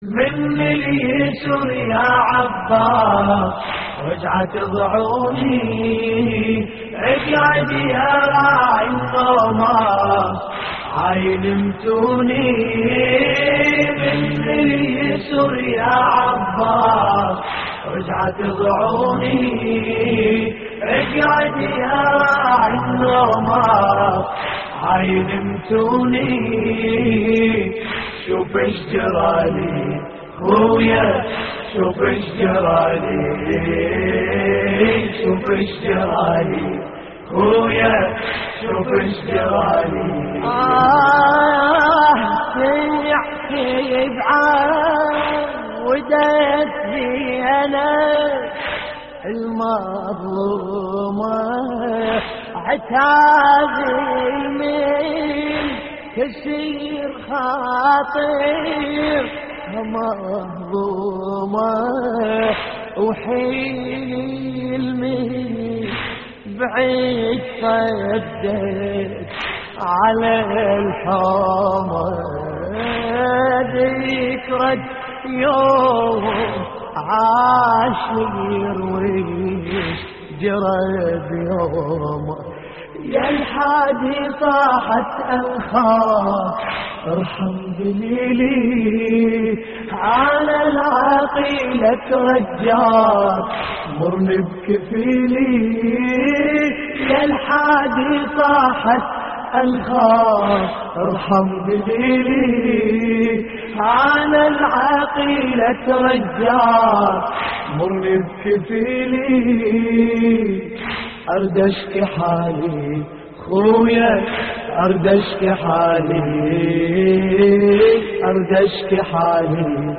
موقع يا حسين : اللطميات الحسينية من اليسر يا عباس رجعت ضعوني - استديو «عودة السبايا» لحفظ الملف في مجلد خاص اضغط بالزر الأيمن هنا ثم اختر (حفظ الهدف باسم - Save Target As) واختر المكان المناسب